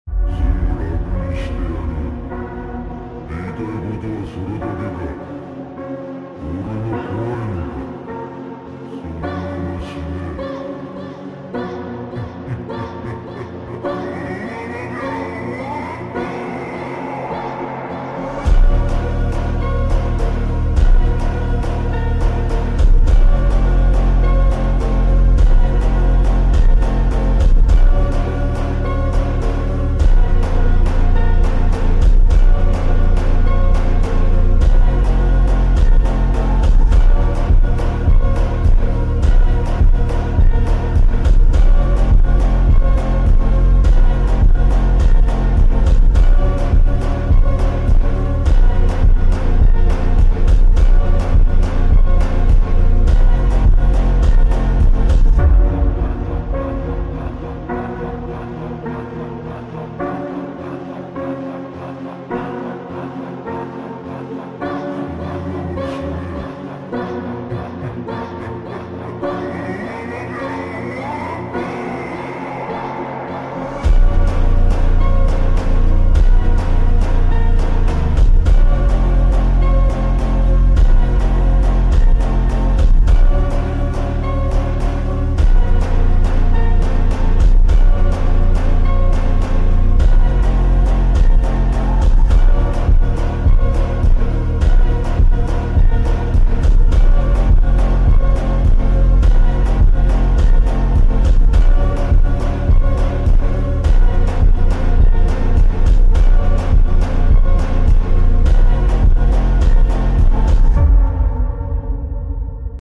Slowed + Reverb + Eco + Bass Boosted × Epicenter Bass